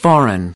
19. foreign (adj) /ˈfɔːr.ən/: nước ngoài